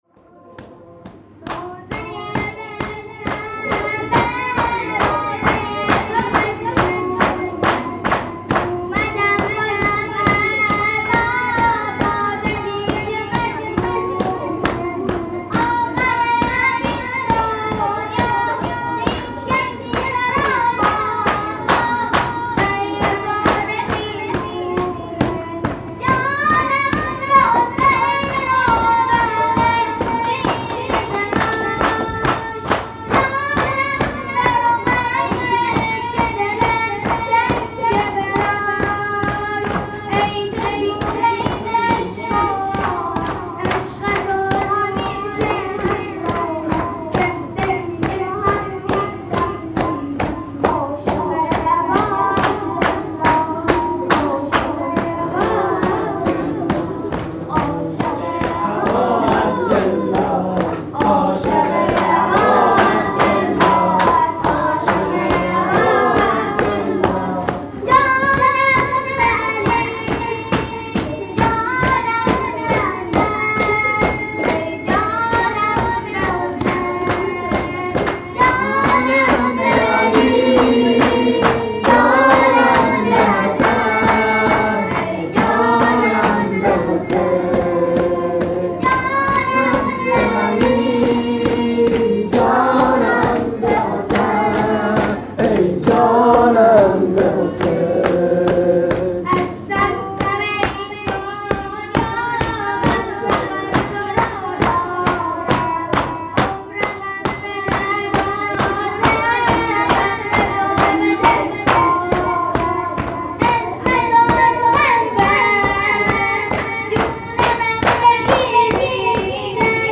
خیمه گاه - شجره طیبه صالحین - هیچ کسی برام نمیمونه _ شور